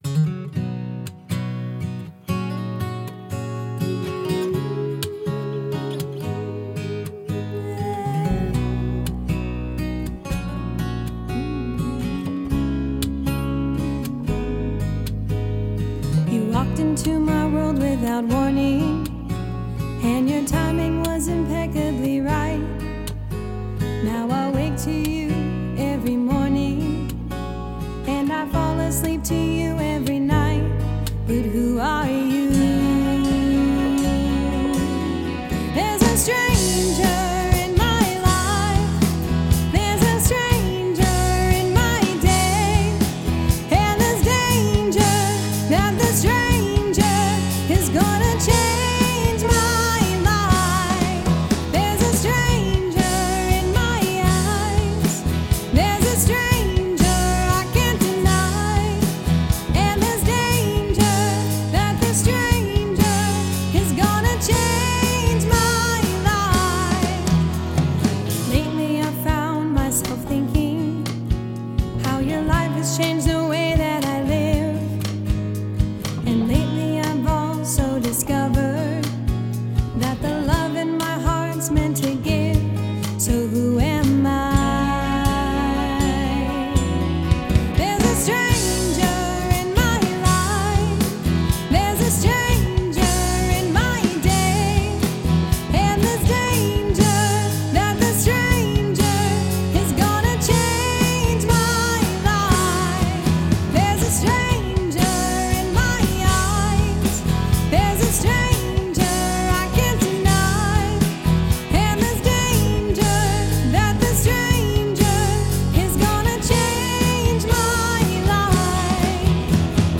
Original Key (B) with Vocal